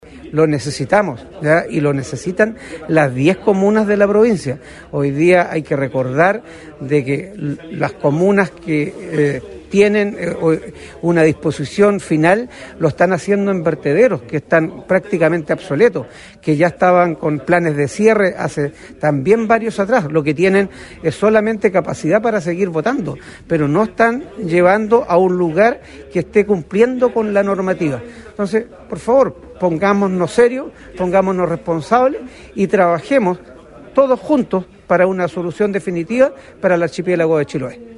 Recordó la autoridad que actualmente existen vertederos que no cumplen la normativa y que por ello se hace necesario abocarse a resolver esa ilegalidad.
11-CARLOS-GOMEZ-ANCUD-2.mp3